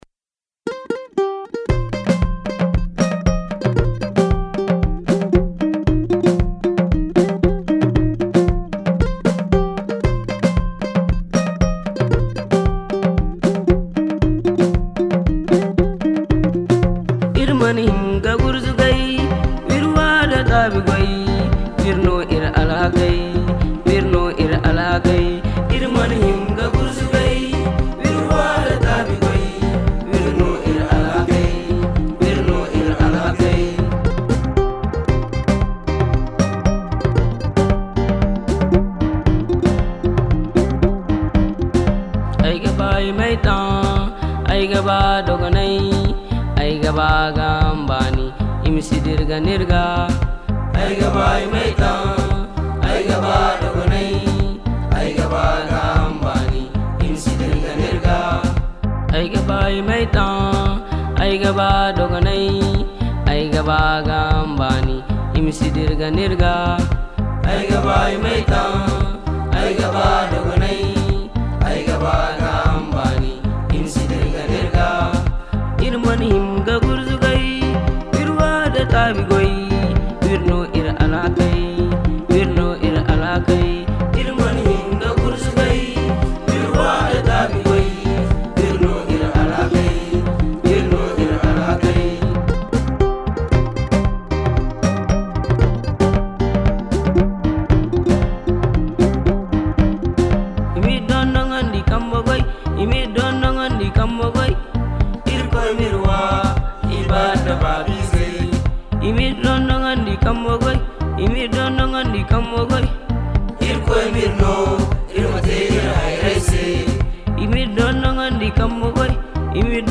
Enregistrées dans le quartier des mineurs de la prison, ces deux œuvres musicales sont des témoignages à cœur ouvert de la vie des enfants incarcérés au Niger …